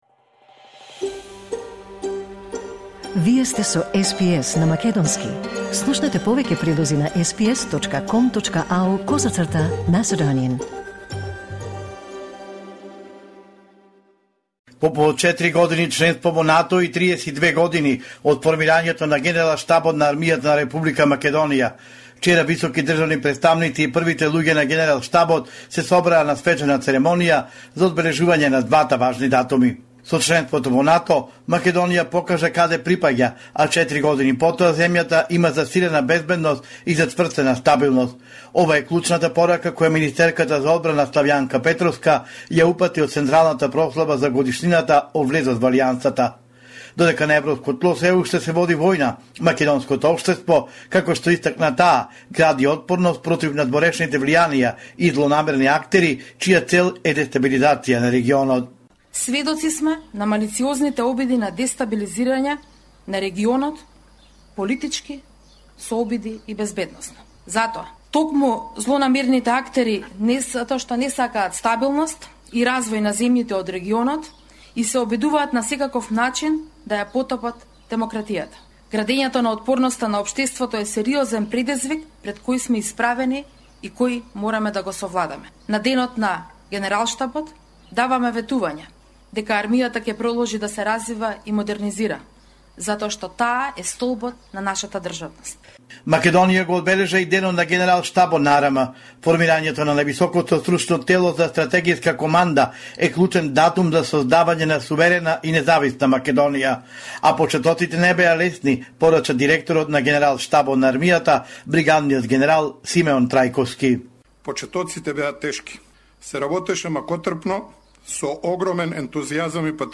Homeland Report in Macedonian 28 March 2024